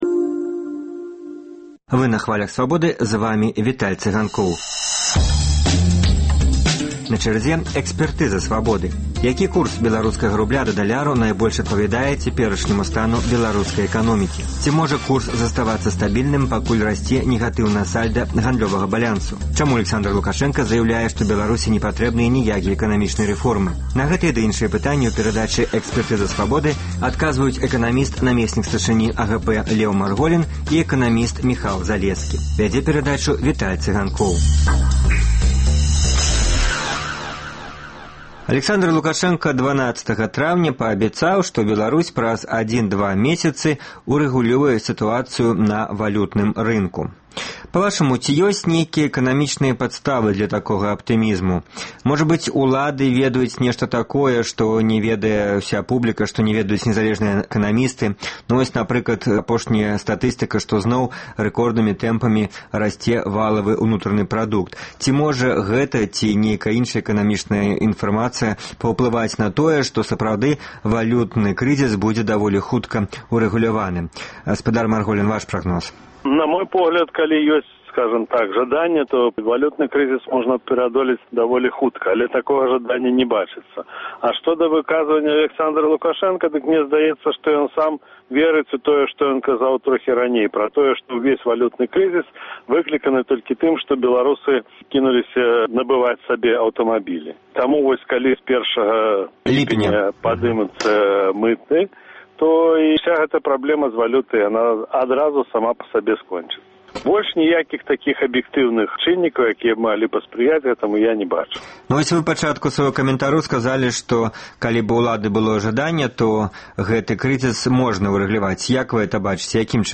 Які курс беларускага рубля да даляра найбольш адпавядае цяперашняму стану беларускай эканомікі? На гэтае ды іншыя пытаньні адказваюць эканаміст